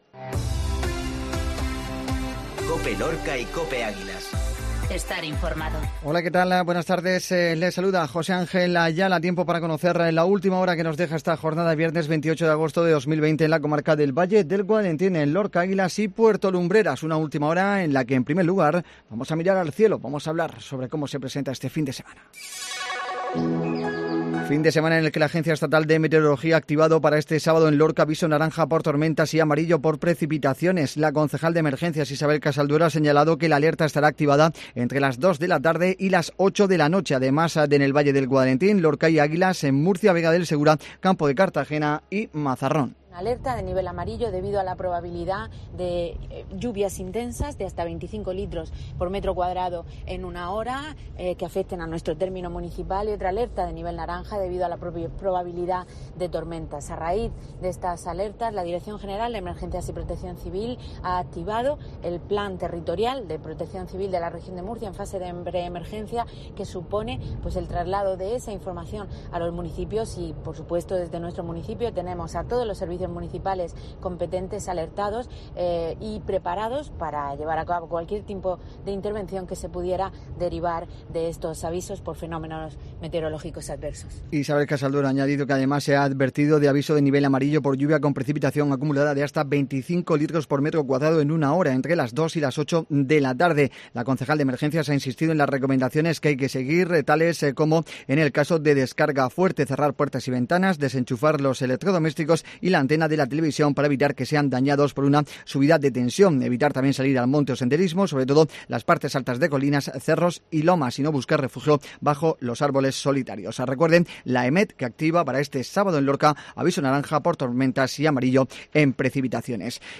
INFORMATIVO MEDIODÍA COPE VIERNES